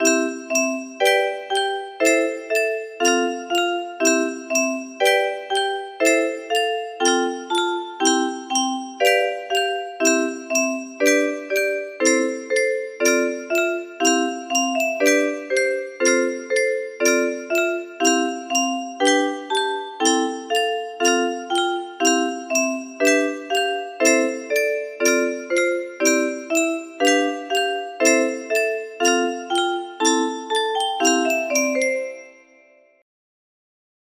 A music box melody
Grand Illusions 30 (F scale)